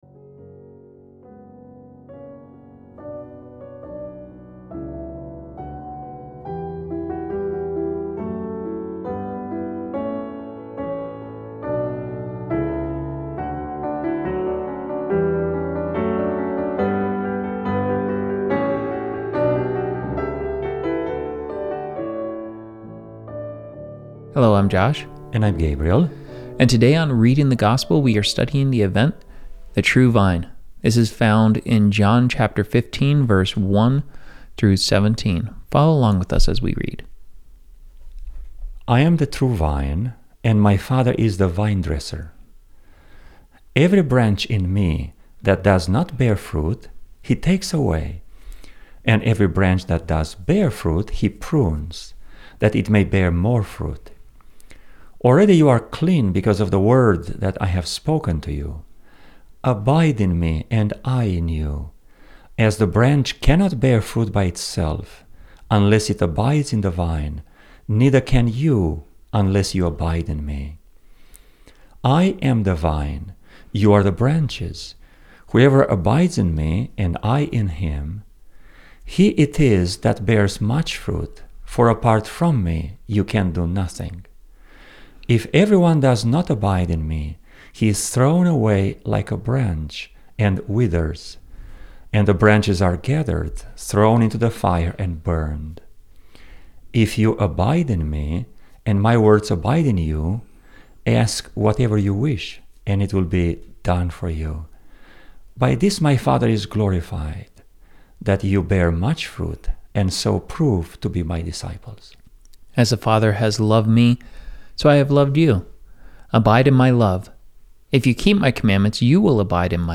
Join us for an enlightening conversation that will inspire and challenge you in your walk with God!